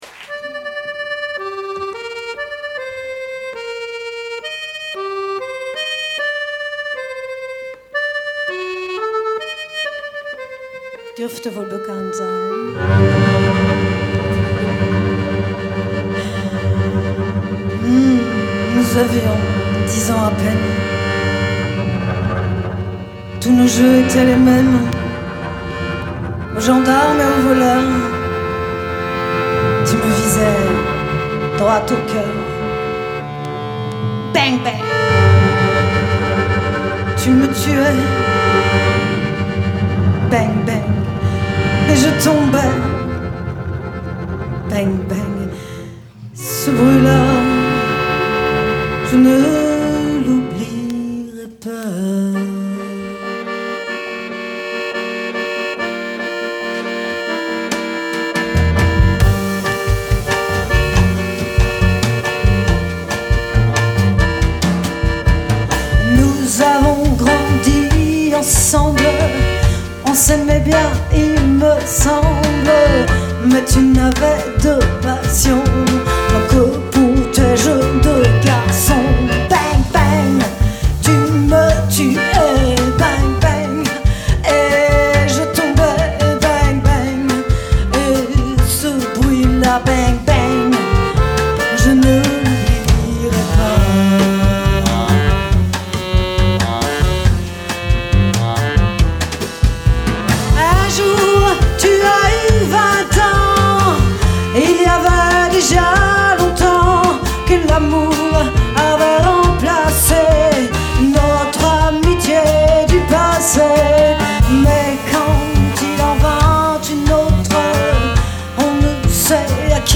Kontrabass
Percussion